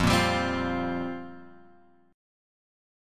Fsus2sus4 chord {1 1 x 0 1 1} chord